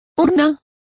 Complete with pronunciation of the translation of urn.